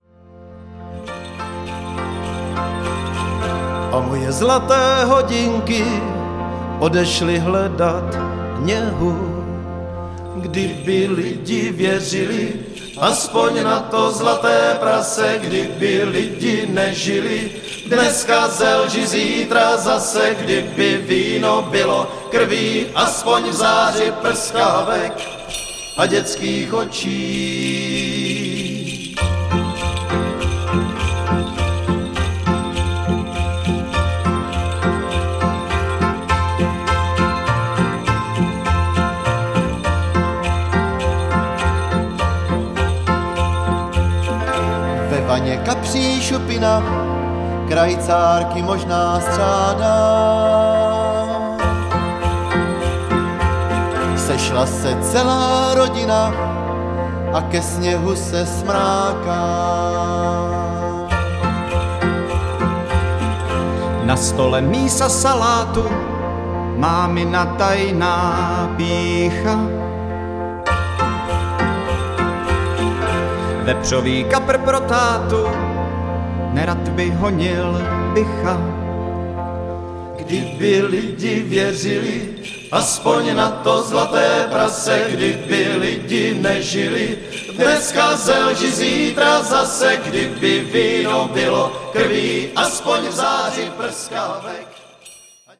zpěv